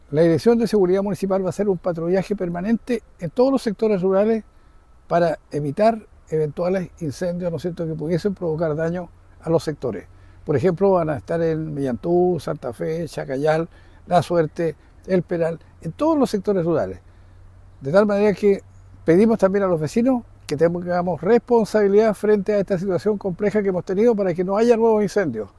En ese sentido, el alcalde de Los Ángeles, José Pérez, señaló que se implementarán patrullajes preventivos en los sectores rurales de la comuna a fin de resguardar la seguridad.